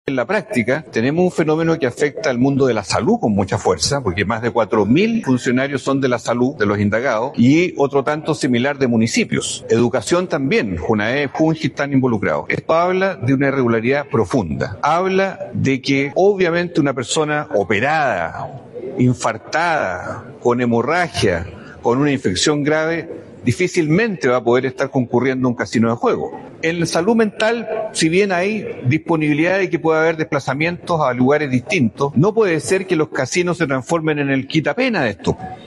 Ante esto, el Senador por la región de O’Higgins, Juan Luis Castro declaró que estamos en un problema de irregularidad muy fuerte.